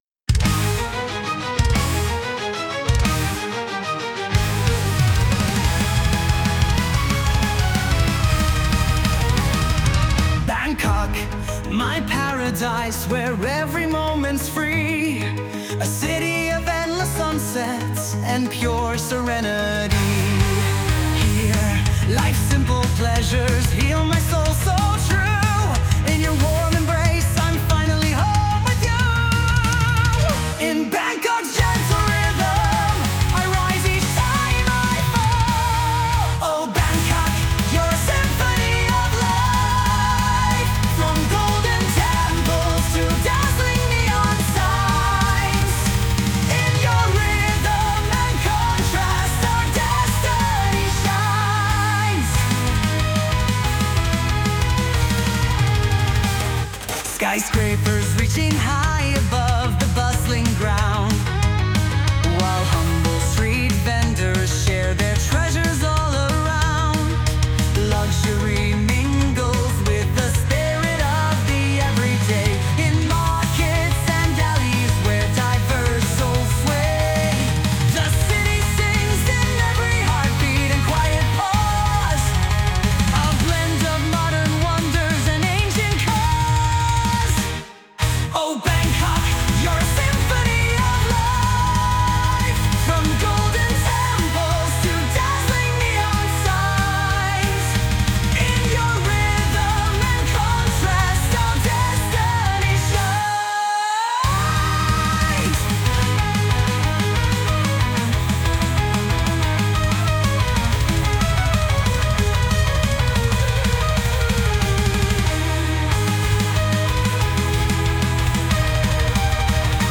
(übrigens auch mit einem IMO sehr gelungenen Male/Female-Mix)